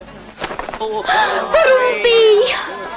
porumbei-3.mp3